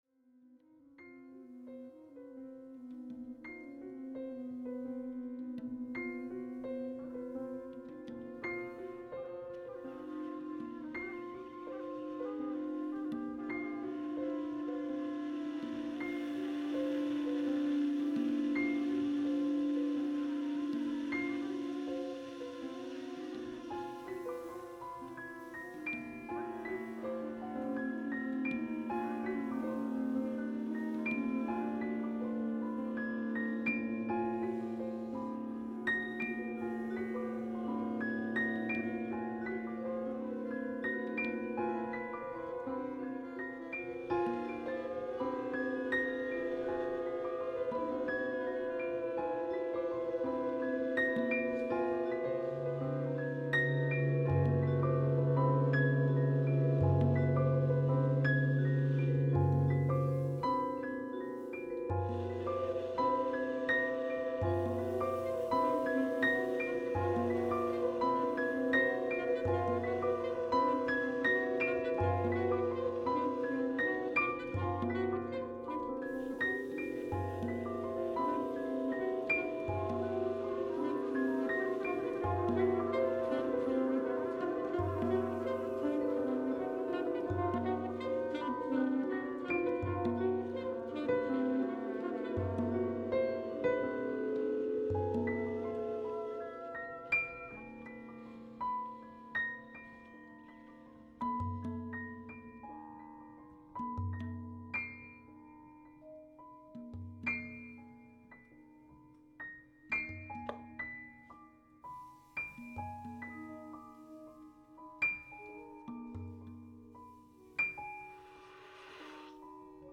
piano
alto sax
tenor sax
trumpet
drums, vibes
drums, tabla
excerpt one Live at IBeam July 20